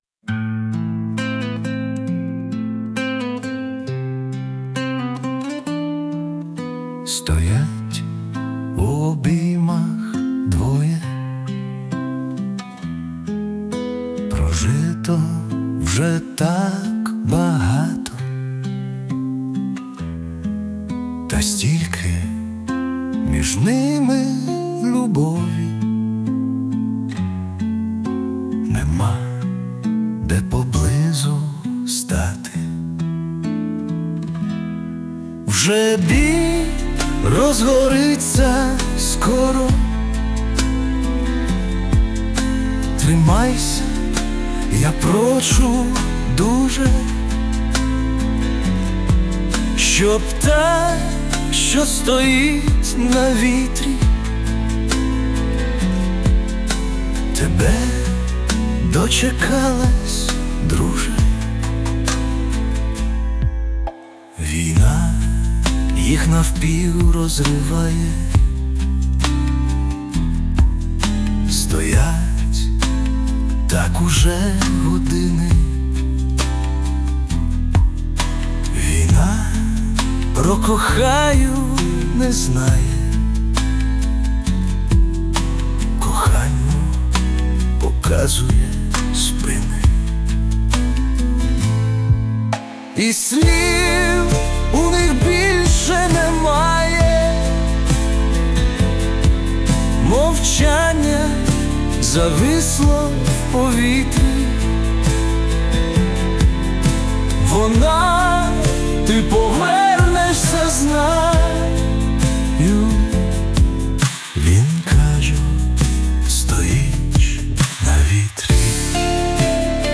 Музичний супровід із залученням ШІ
СТИЛЬОВІ ЖАНРИ: Ліричний